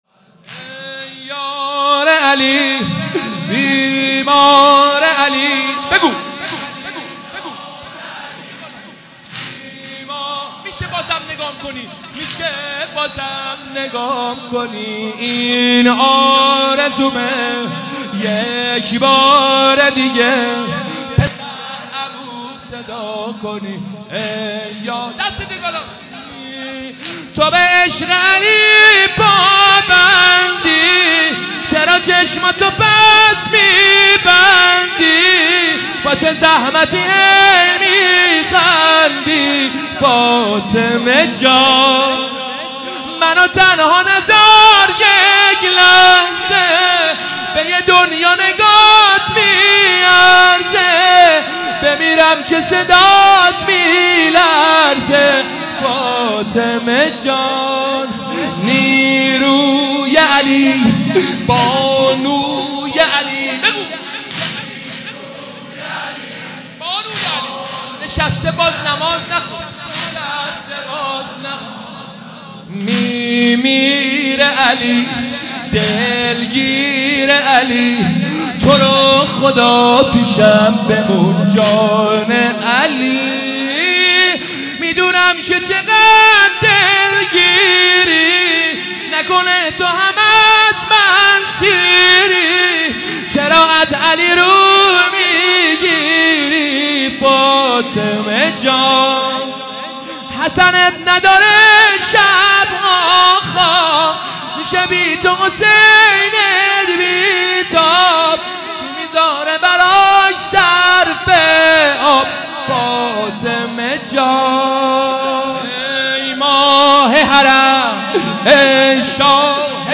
مداحی
ایام فاطمیه 1439 | محفل عزاداران حضرت زهرا (س) شاهرود